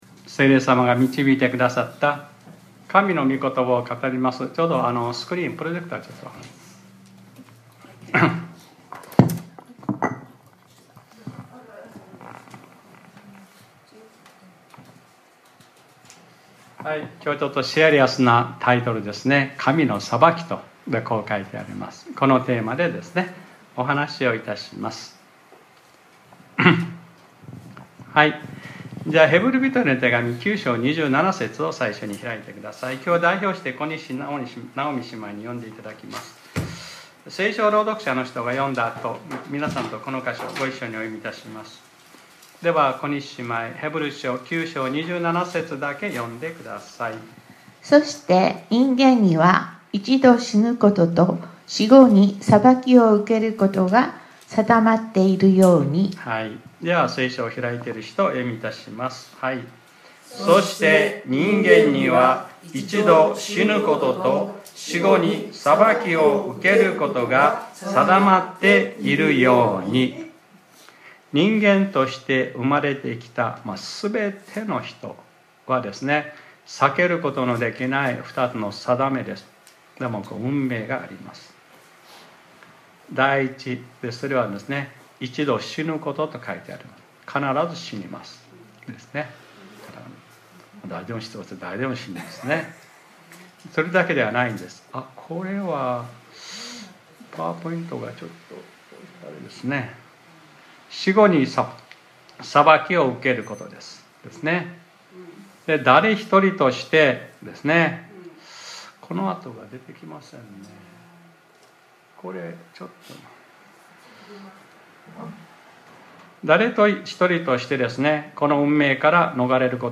2023年10月08日（日）礼拝説教『 神のさばき 』